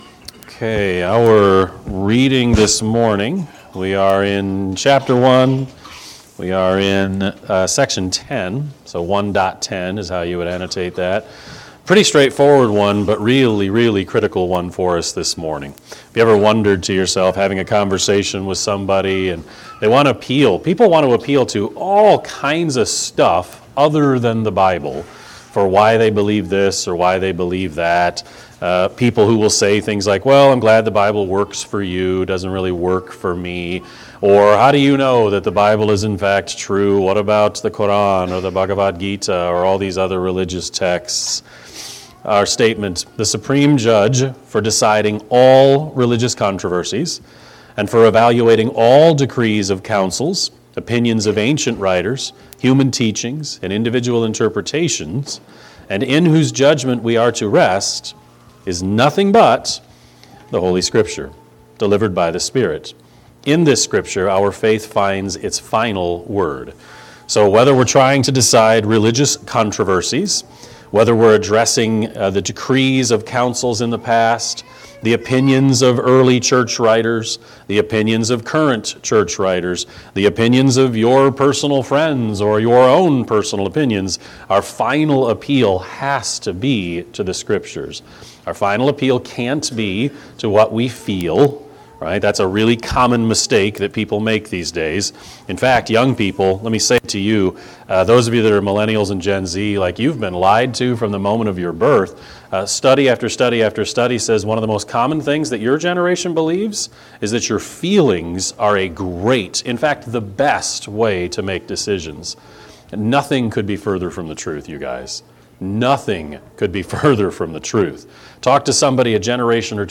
Sermon-3-2-25-Edit.mp3